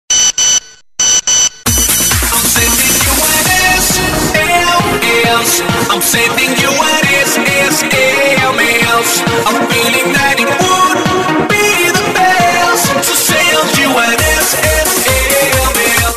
Message Tones
funny cute voice kid korean